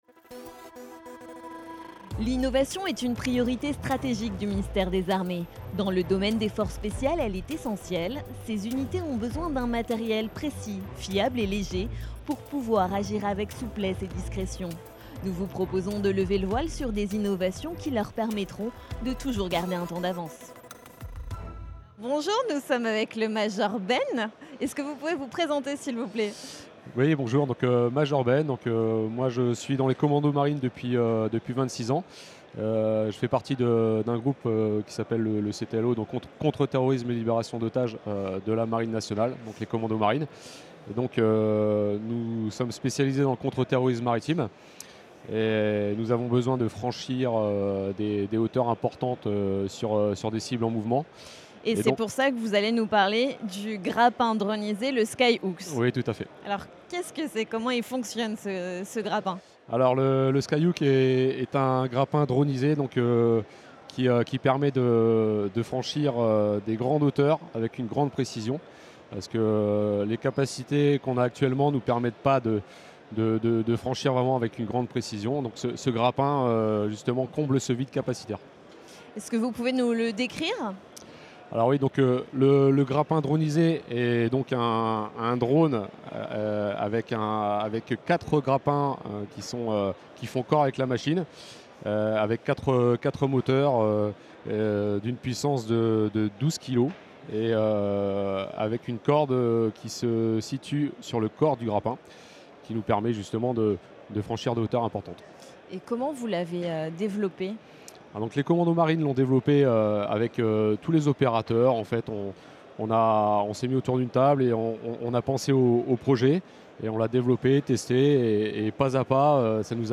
A l’occasion de la Journée mondiale de la créativité et de l’innovation, la rédaction vous propose, pendant deux jours, d’écouter des innovateurs civils et militaires qui ont mobilisé leurs idées et talents au service des forces spéciales.